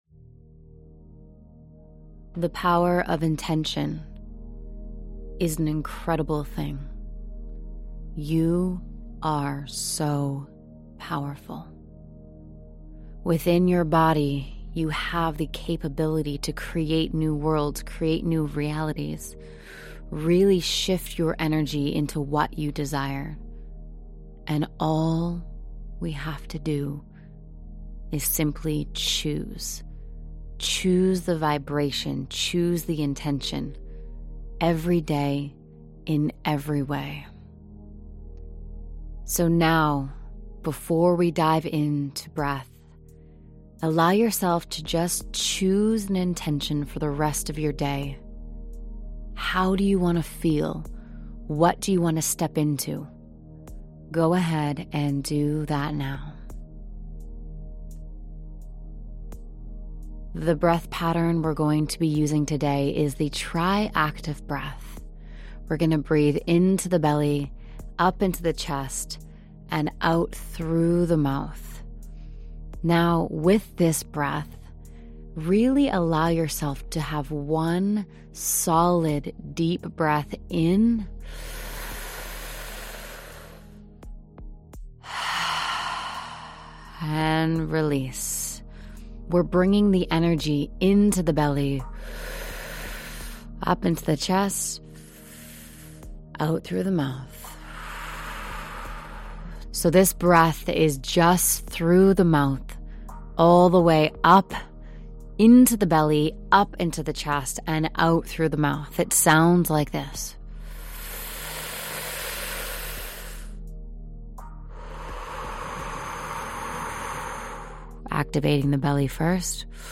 Yes! Here is your free Breathwork Audio
Listen to the short, FREE, 10-min breathwork session below and experience what breathwork can do for you at a much deeper level.